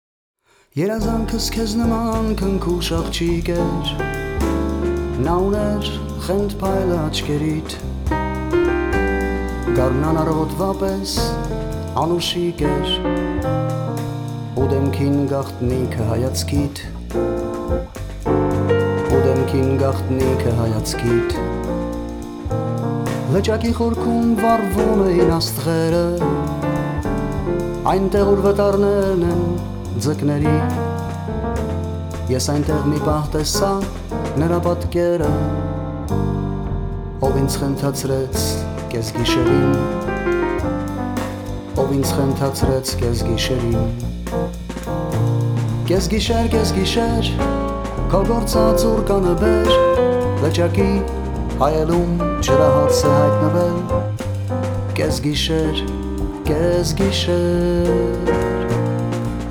PIANO & SYNTH
BASS
DRUMS
CELLO
VOCALS